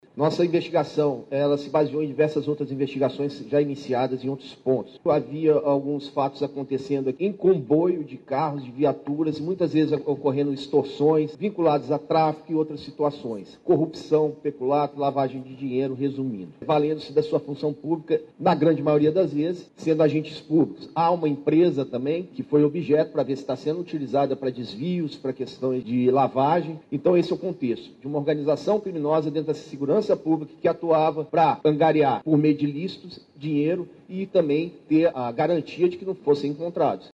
De acordo com a investigação, alguns coordenadores da alta cúpula da secretaria organizavam e faziam diligências para extorquir traficantes, como explica o Promotor de Justiça e coordenador do GAECO, Igor Starling.